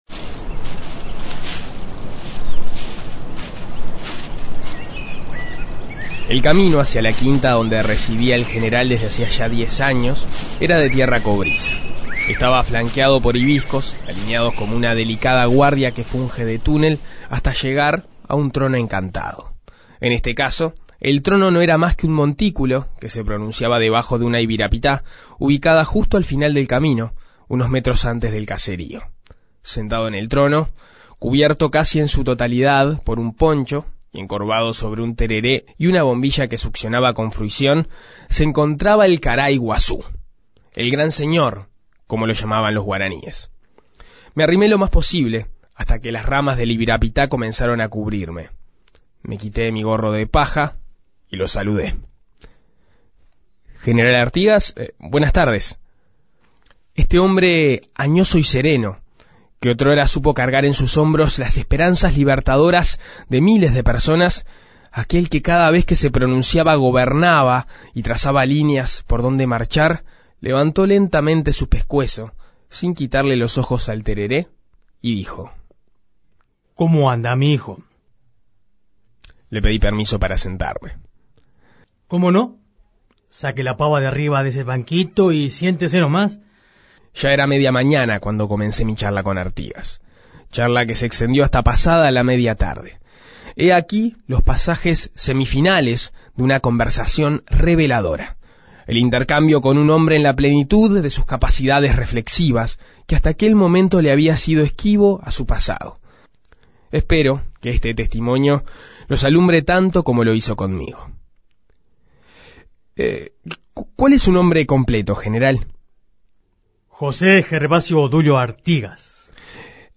Entrevista con José Artigas
Si tuviéramos la máquina del tiempo y enviáramos un periodista a Paraguay a entrevistar a Artigas, éste tendría mil voces: las voces de cada una de las personas que integran la sociedad uruguaya. De aquí nace una entrevista inédita, una entrevista artiguista.